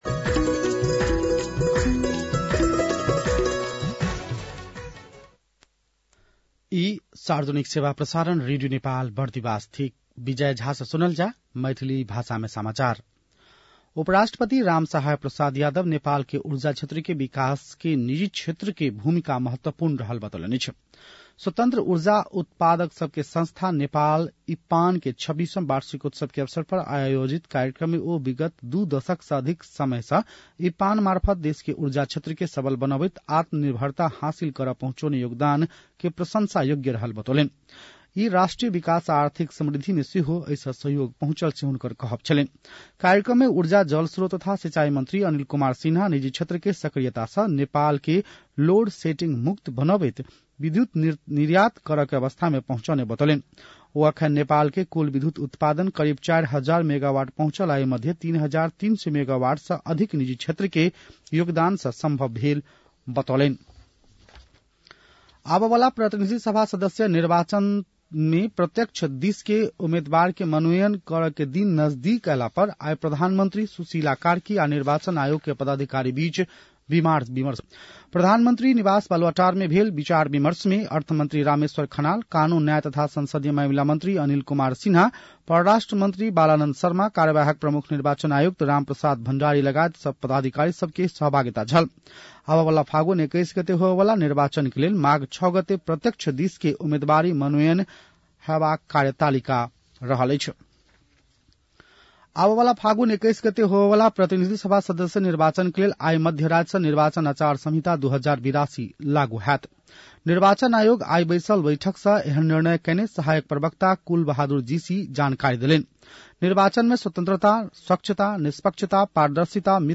मैथिली भाषामा समाचार : ४ माघ , २०८२
Maithali-news-10-04-.mp3